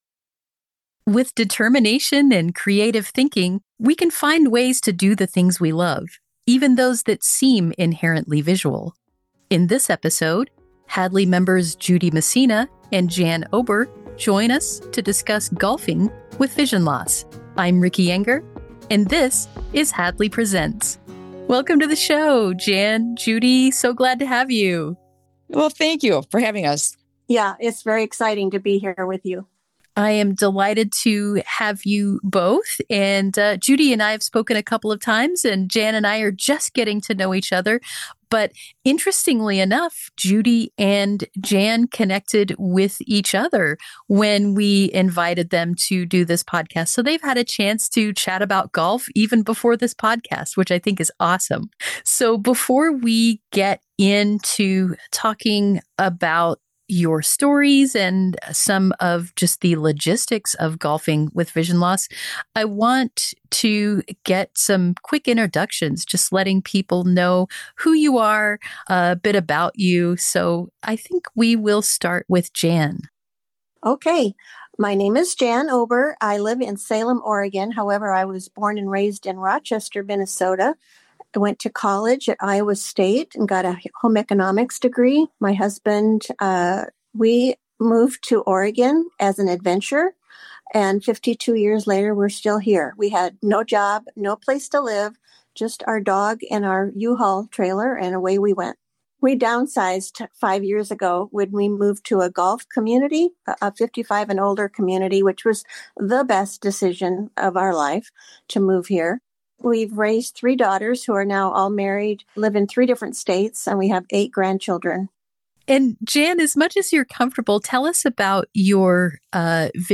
An episode of the Hadley Presents: A Conversation with the Experts audio podcast